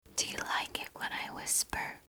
(Girl) - Do you like it when I whisper?
Category: ASMR Mood: Relax Editor's Choice